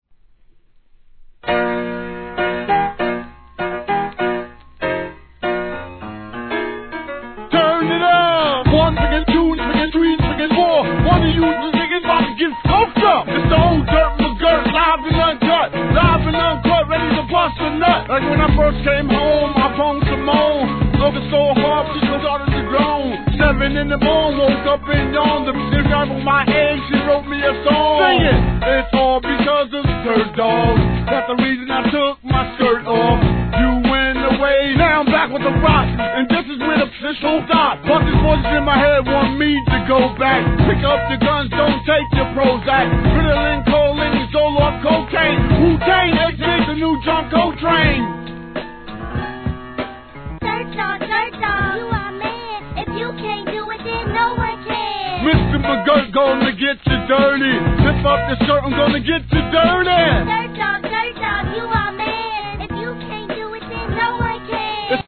HIP HOP/R&B
印象的なPIANOのLOOPに相変わらずの切れたRAPを披露!